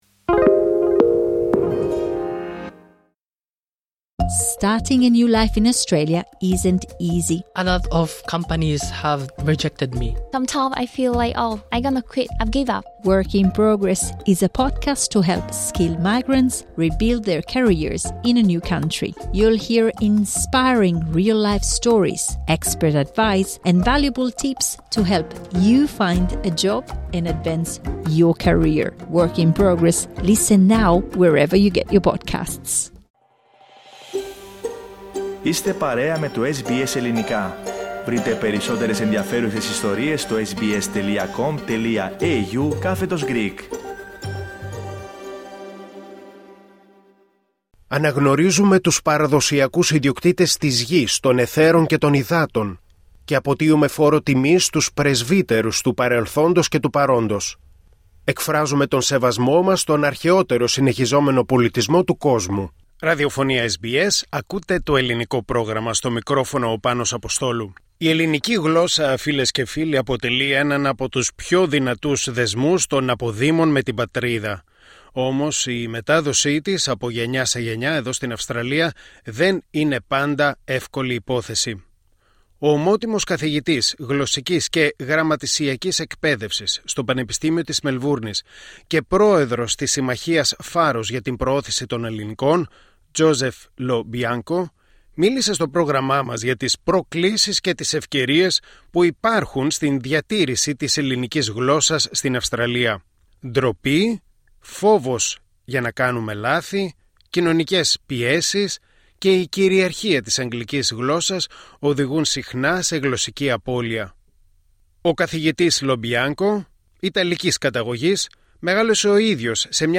LISTEN TO Πώς να ξεπεράσουμε την ντροπή και να μιλήσουμε ελληνικά στην Αυστραλία 09:09 Στη συνέντευξη, ο καθηγητής ήταν σαφής: η απώλεια της γλώσσας στις μεταναστευτικές κοινότητες είναι φυσιολογικό φαινόμενο. Μέσα σε δύο με τρεις γενιές, η μητρική γλώσσα δίνει τη θέση της στην κυρίαρχη γλώσσα της χώρας υποδοχής, σε αυτή την περίπτωση στα αγγλικά.